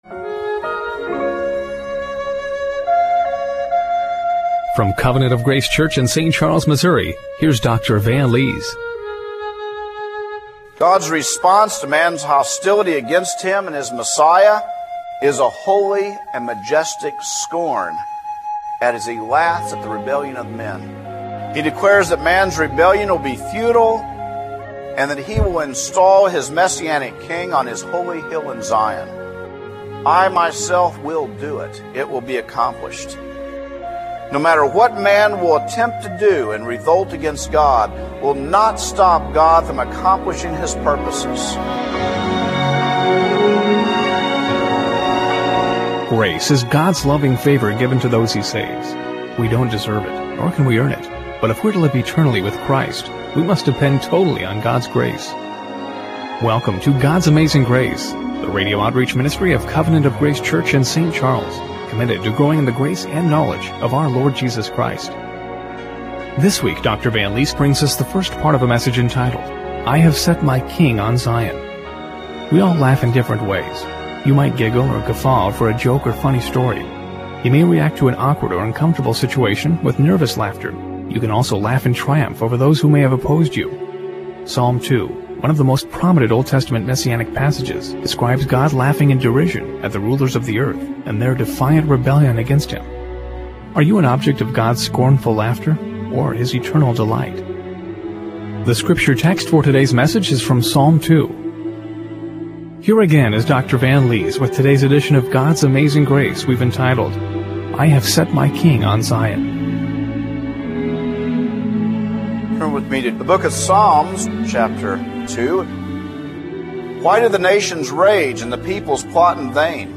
Psalm 2:1-12 Service Type: Radio Broadcast Are you an object of God's scornful laughter